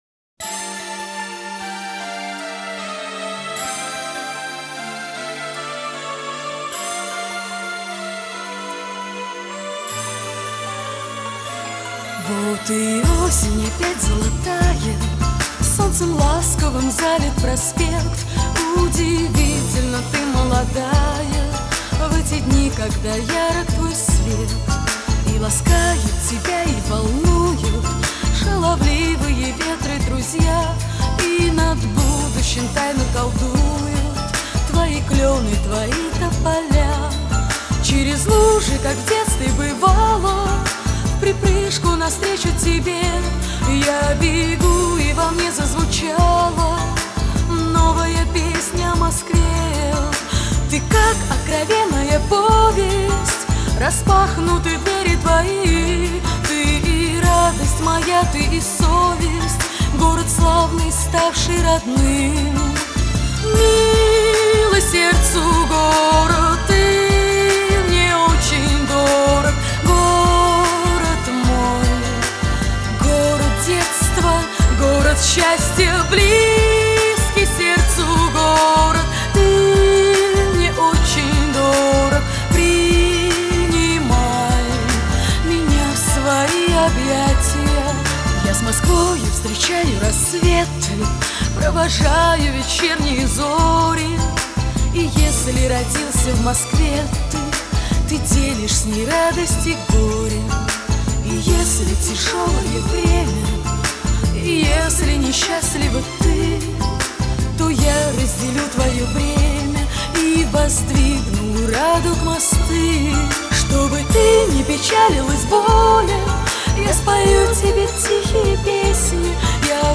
Лирика Романтика